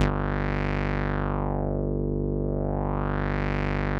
Index of /90_sSampleCDs/Trance_Explosion_Vol1/Instrument Multi-samples/Wasp Dark Lead
G1_wasp_dark_lead.wav